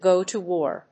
アクセントgò to wár